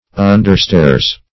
Understairs \Un"der*stairs`\, n. The basement or cellar.